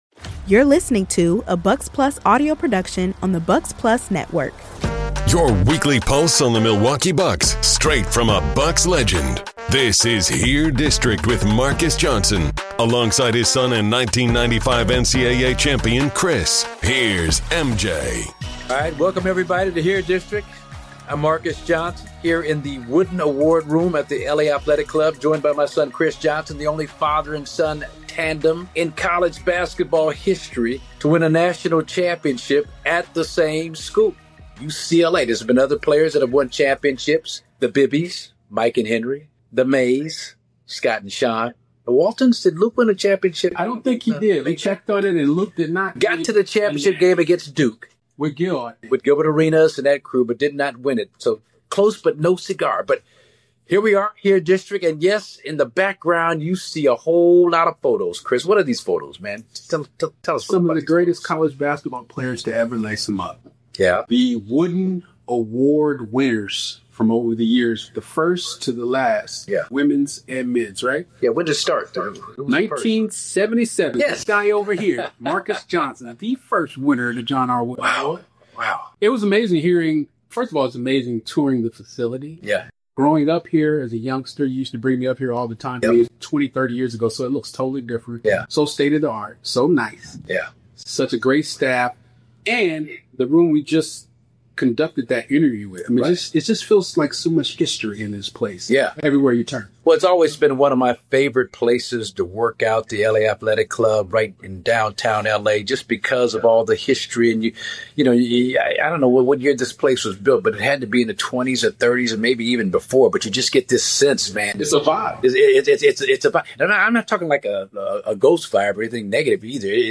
joins us in studio